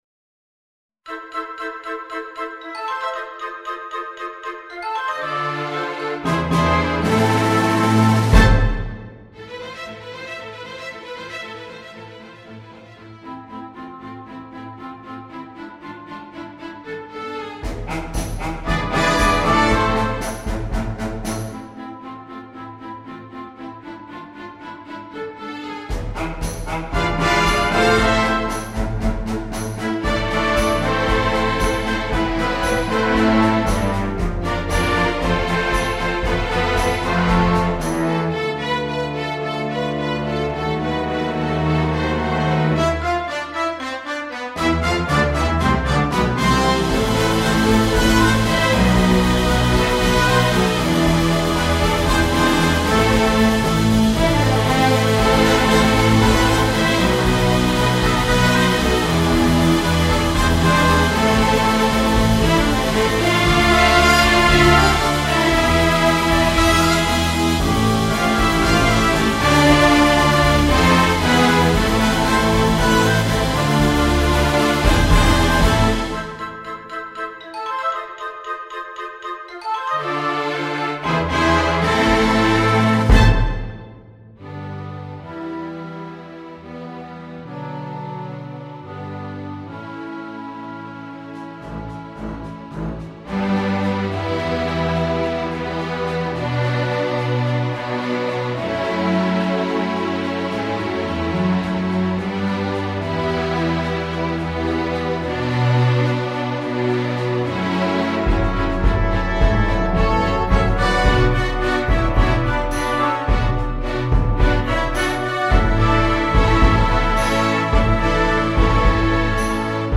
Besetzung: Sinfonieorchester
Streicher (Violine 1, Violine 2, Viola, Cello, Kontrabass)